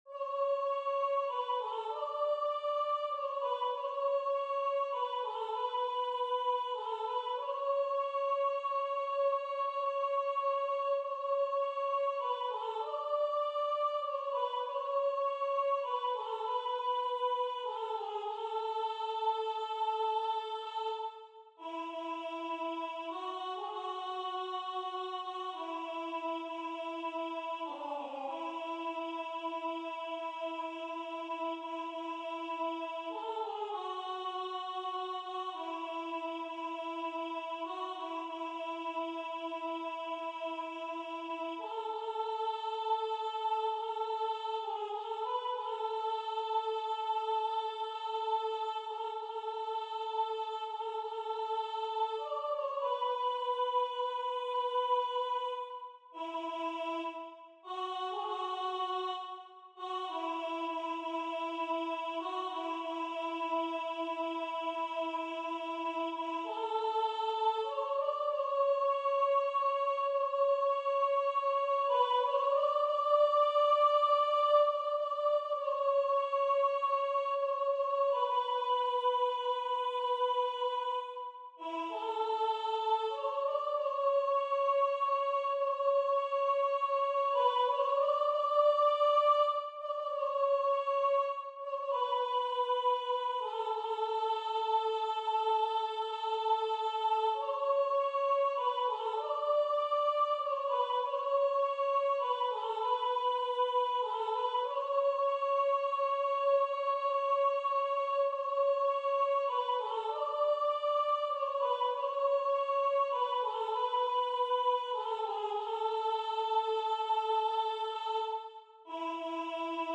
Soprano AZKEN DANTZA
AZKEN-DANTZA-Soprano.mp3